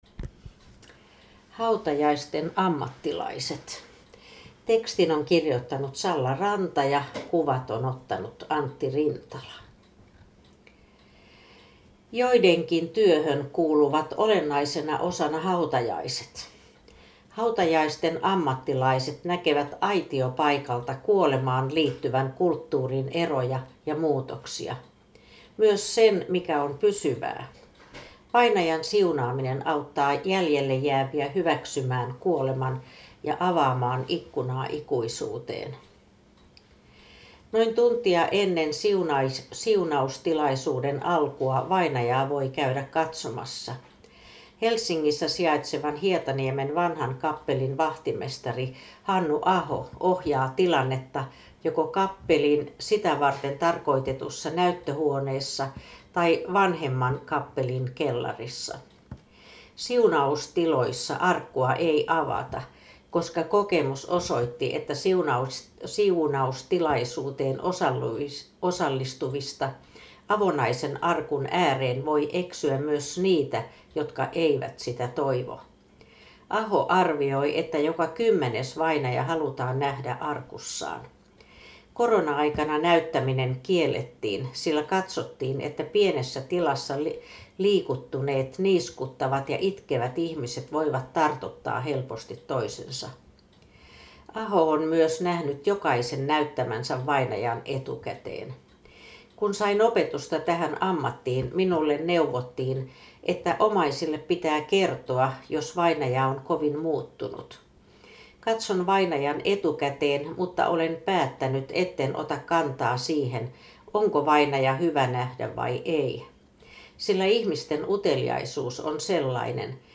Kuuntele reportaasi luettuna.